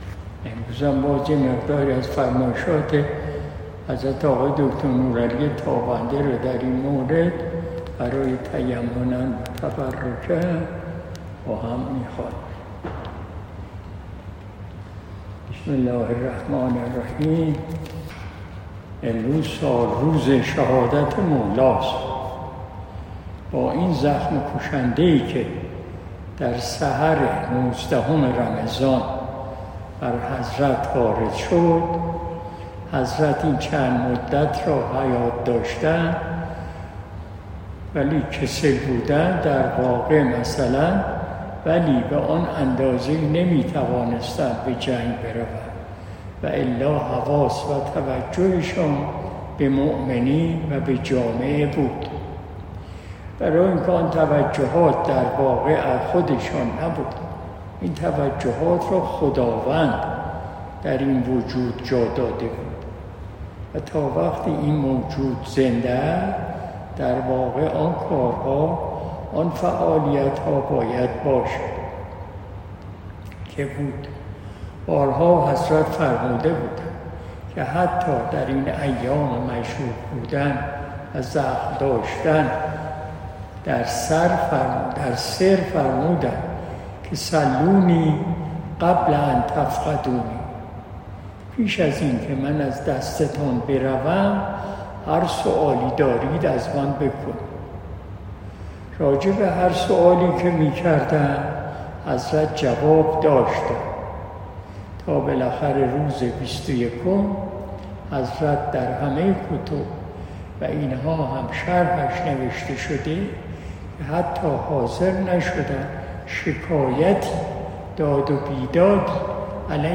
قرائت